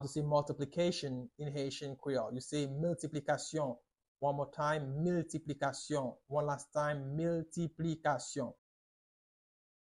Pronunciation:
19.How-to-say-Multiplication-in-Haitian-Creole-–-Miltiplikasyon-with-pronunciation.mp3